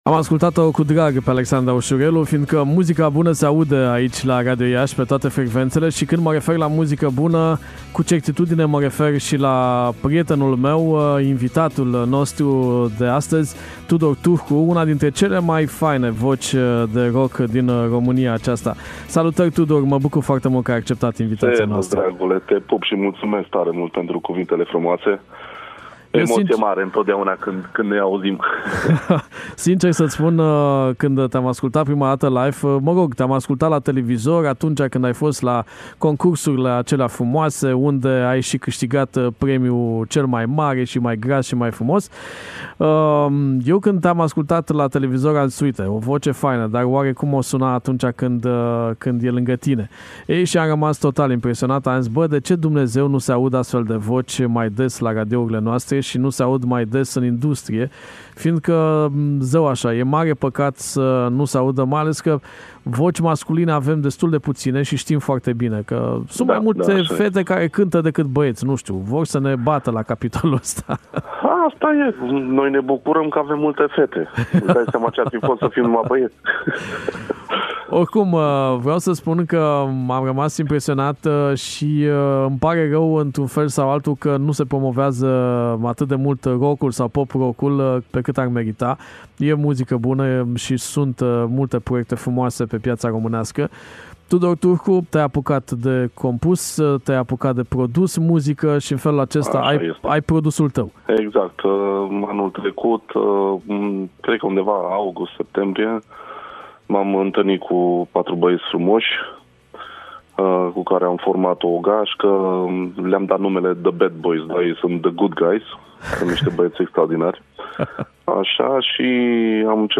E rock dar fără accente dure. E aceeaşi voce extraordinară care se evidenţiază prin timbralitate, muzicalitate şi sinceritate. E un proiect independent şi curajos, fără compromisuri.